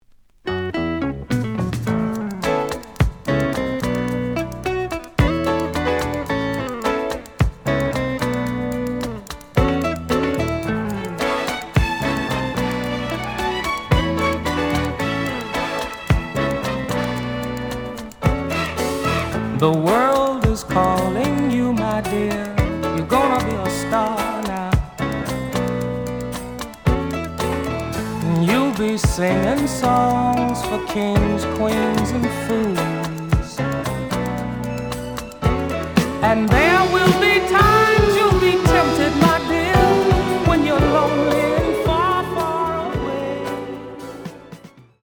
The audio sample is recorded from the actual item.
●Genre: Reggae